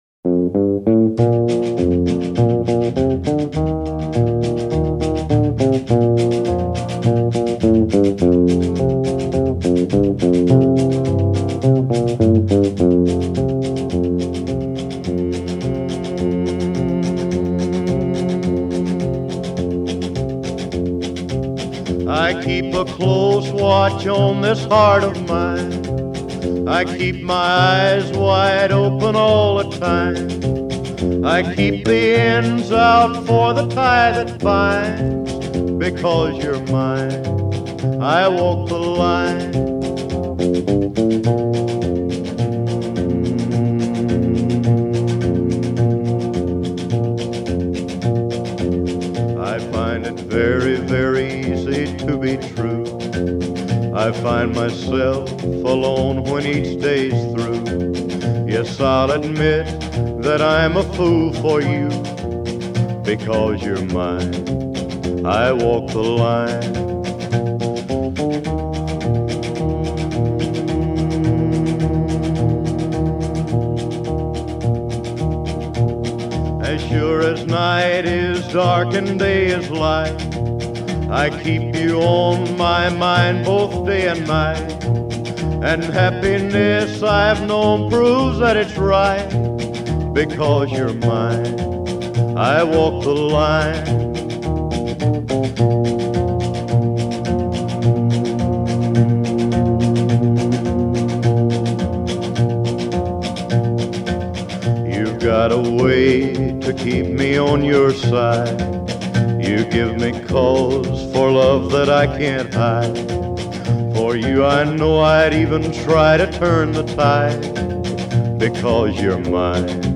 baladas country